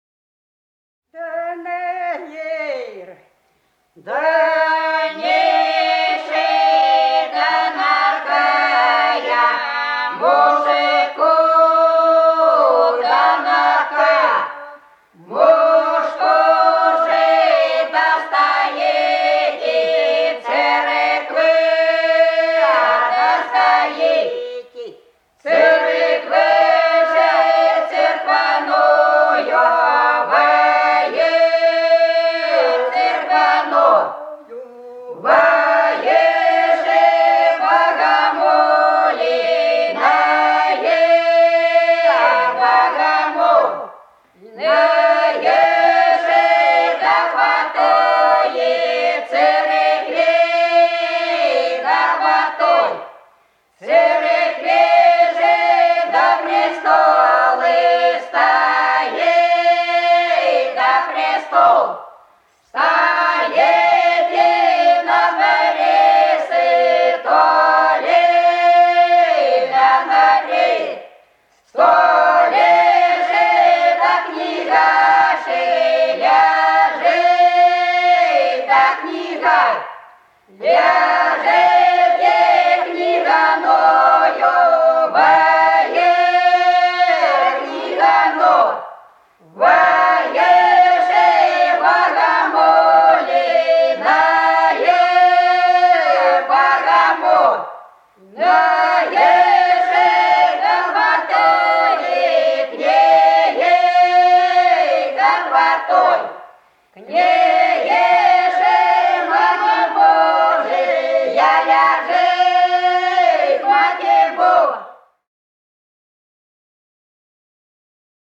Голоса уходящего века (село Фощеватово) Да на Ердане (в пост)
5._Да_на_Ердане_(в_пост)._Хоровая_версия.mp3